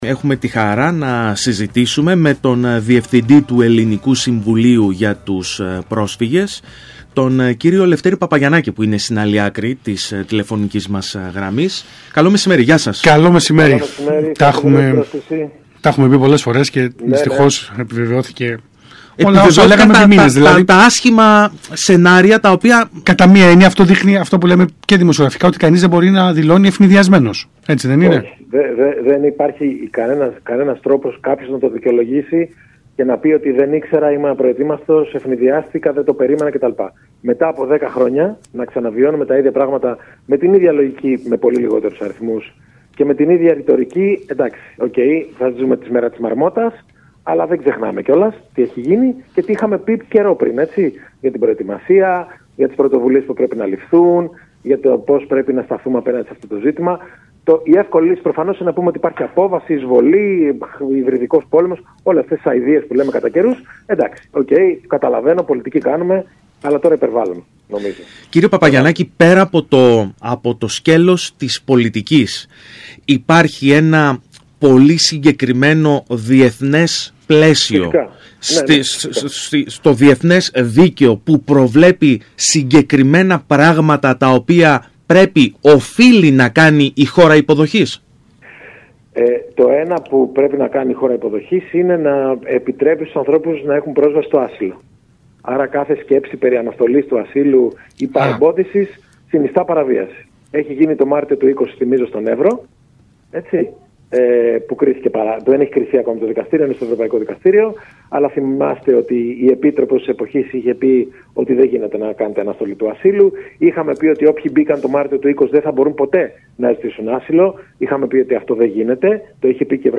μιλώντας το μεσημέρι της Τετάρτης 9 Ιουλίου στον ΣΚΑΙ Κρήτης 92.1.